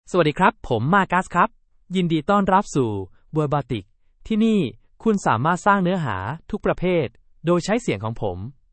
MaleThai (Thailand)
Marcus — Male Thai AI voice
Marcus is a male AI voice for Thai (Thailand).
Voice sample
Listen to Marcus's male Thai voice.